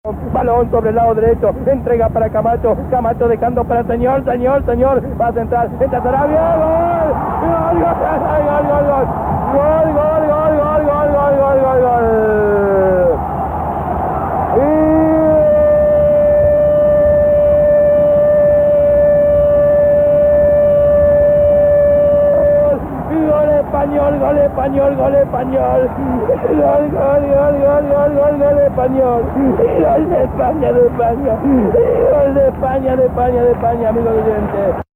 Narració del gol de Manua Sarabia de la selecció espanyola en el partit dvant la selecció de Malta en la fase de classificació per al Campionat d'Europa de 1984.
Esportiu